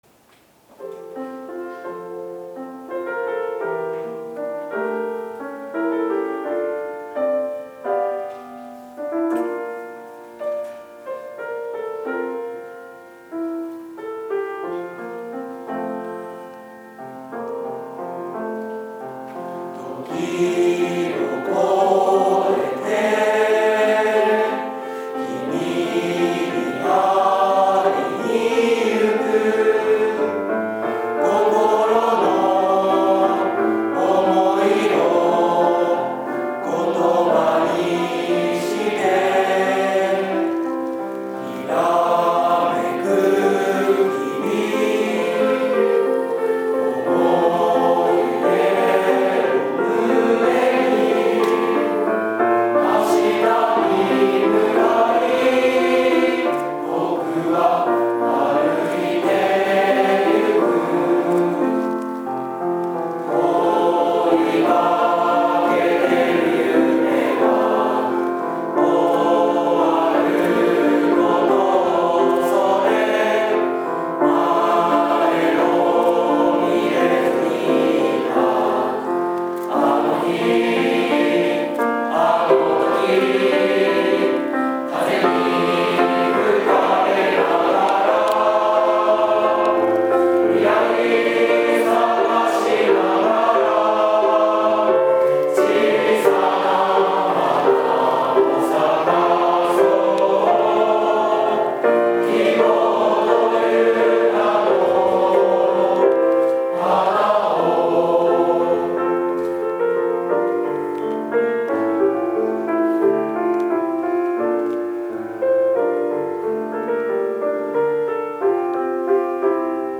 卒業の歌「希望という名の花を」 前に向かう大切さに気付いた58期生全員で歌いました。